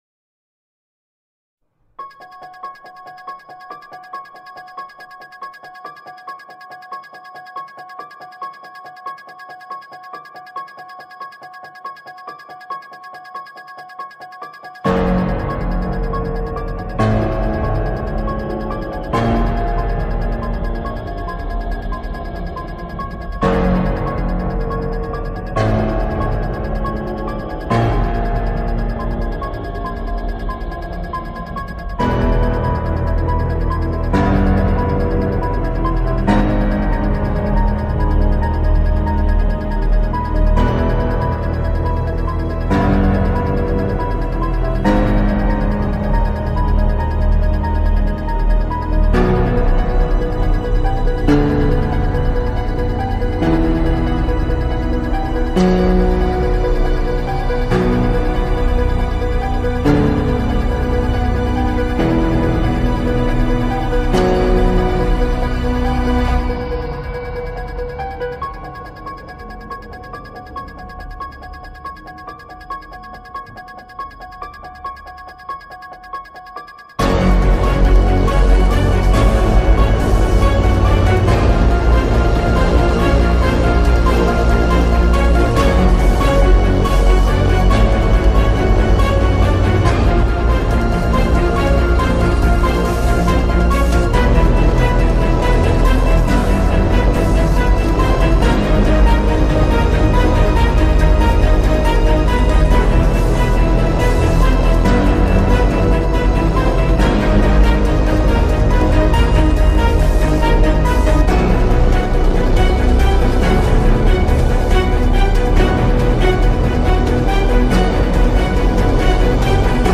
Film Score, Classical, Electronic